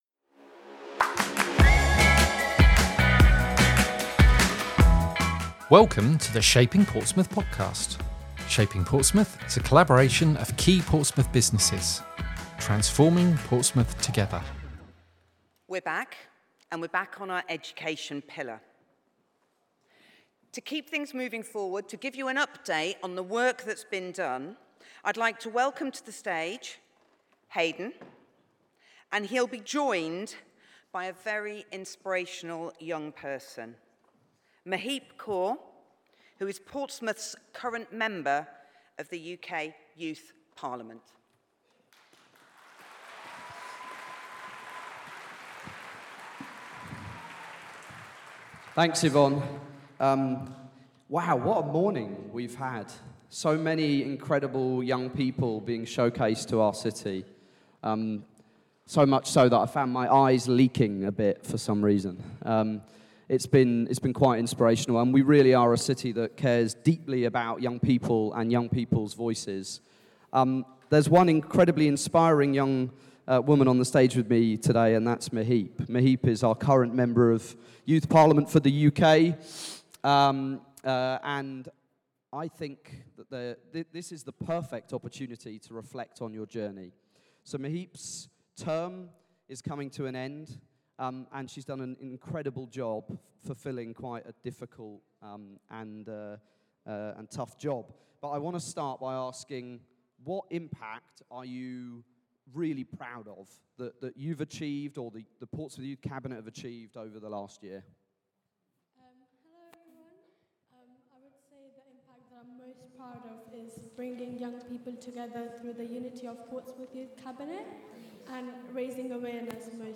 Recorded live at the Shaping Portsmouth Conference on Friday 16th January 2026